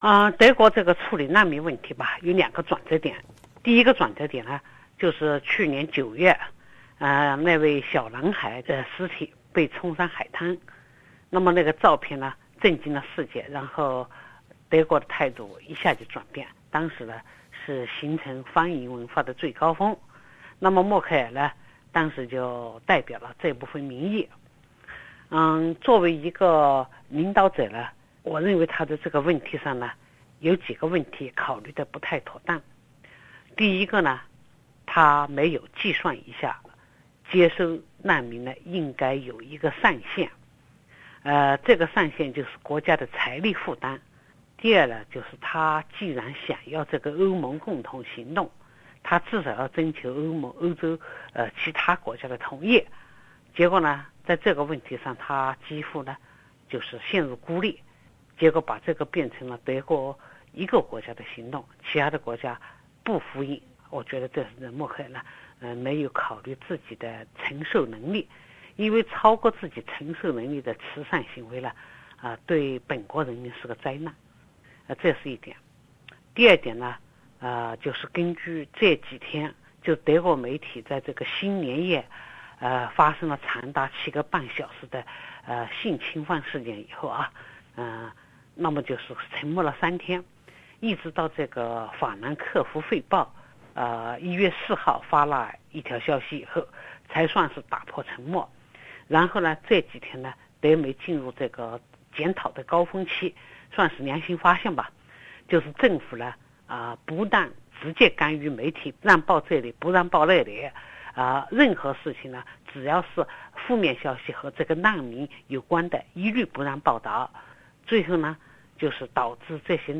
著名学者何清涟采访：科隆事件对德国影响深远
请听本台记者对著名学者何清涟的相关采访。